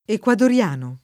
vai all'elenco alfabetico delle voci ingrandisci il carattere 100% rimpicciolisci il carattere stampa invia tramite posta elettronica codividi su Facebook equadoregno [ ek U ador % n’n’o ] e equadoriano [ ek U ador L# no ] → ecuadoriano